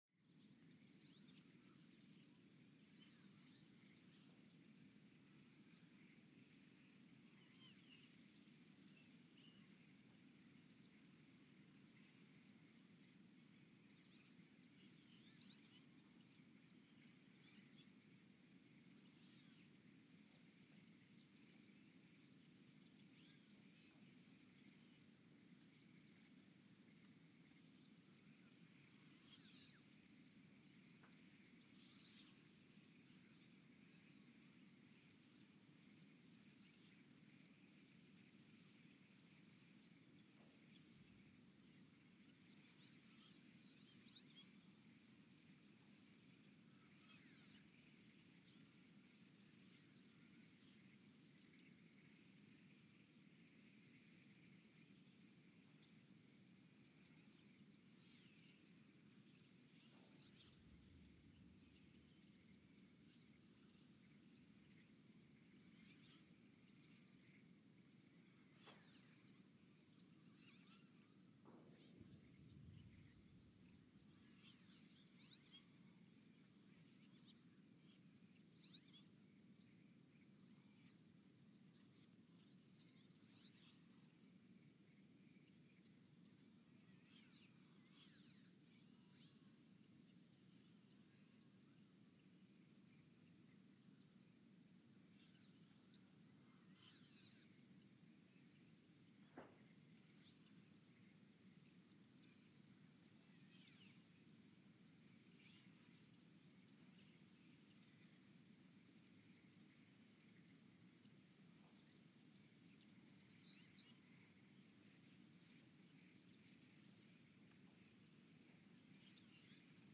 The Earthsound Project is an ongoing audio and conceptual experiment to bring the deep seismic and atmospheric sounds of the planet into conscious awareness.
Station : ULN (network: IRIS/USGS ) at Ulaanbaatar, Mongolia Sensor : STS-1V/VBB
Speedup : ×900 (transposed up about 10 octaves)
Loop duration (audio) : 11:12 (stereo)